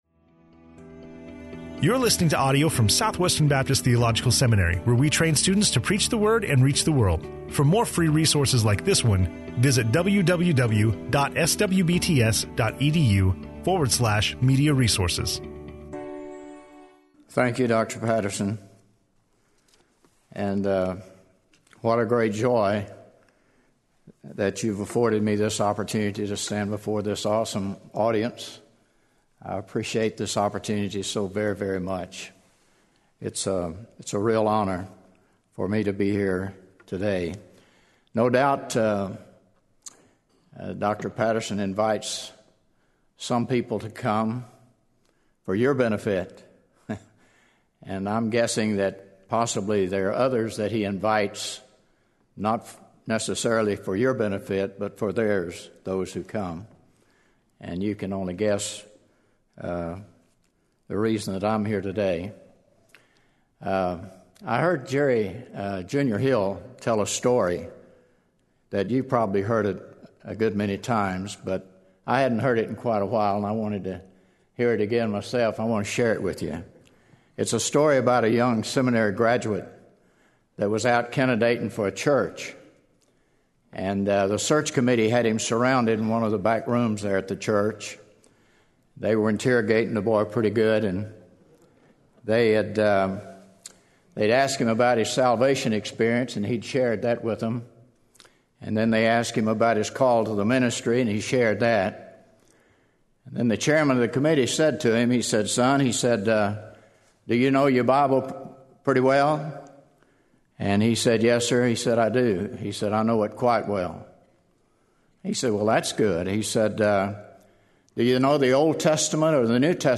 Testimony
speaking on in SWBTS Chapel